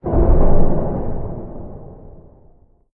Commotion4.ogg